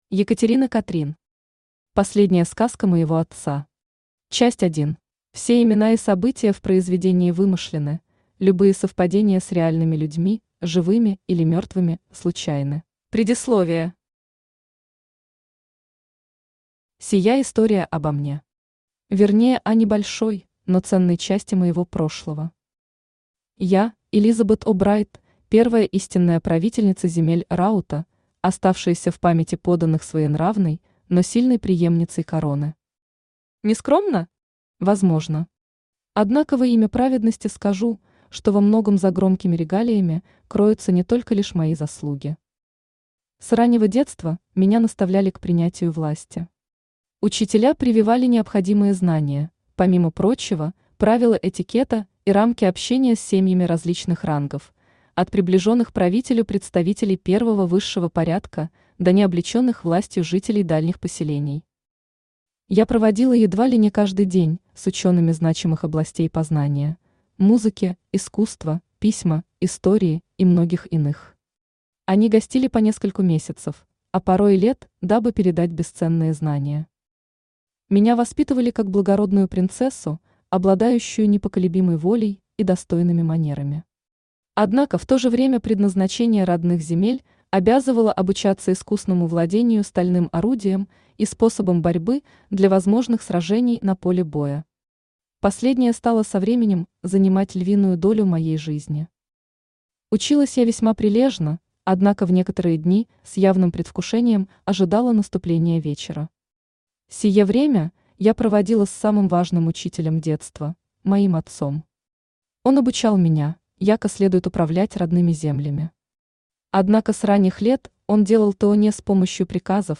Аудиокнига Последняя сказка моего отца. Часть 1 | Библиотека аудиокниг
Часть 1 Автор Екатерина Катрин Читает аудиокнигу Авточтец ЛитРес.